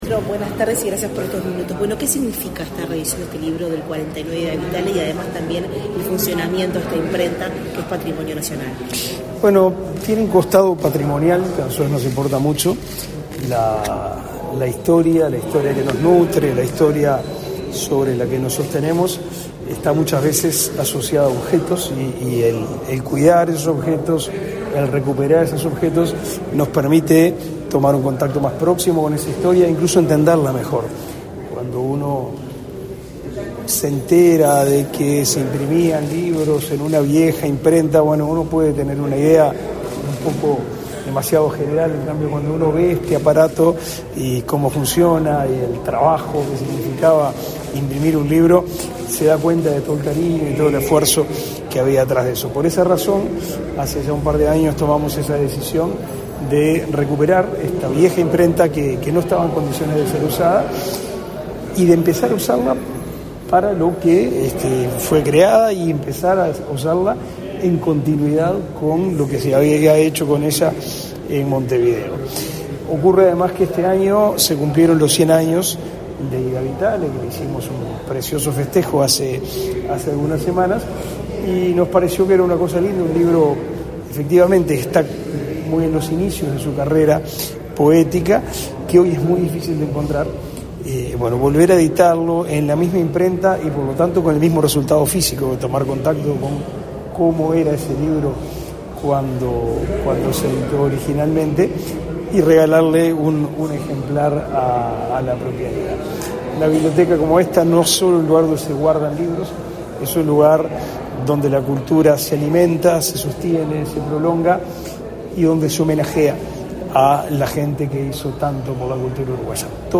Declaraciones del ministro de Educación y Cultura, Pablo da Silveira
En el marco del homenaje a la poeta uruguaya Ida Vitale por el centenario de su nacimiento, este 29 de noviembre, oportunidad en la cual se reeditó el